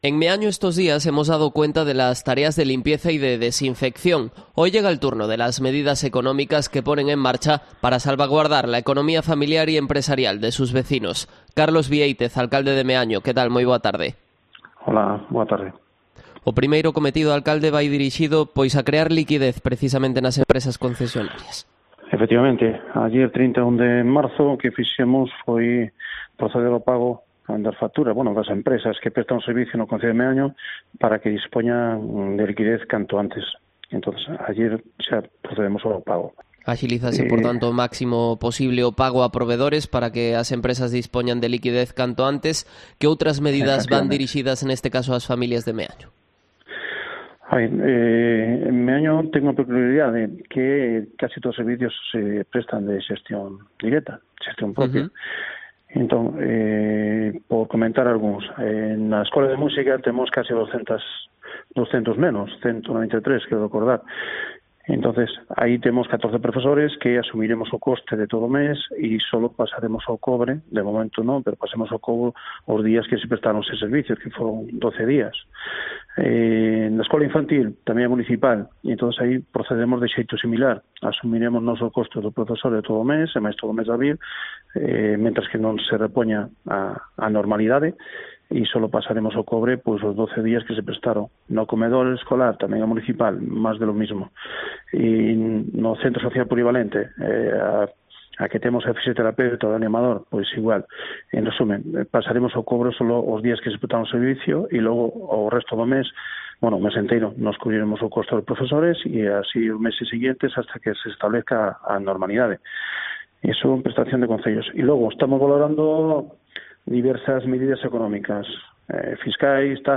Entrevista a Carlos Viéitez, alcalde de Meaño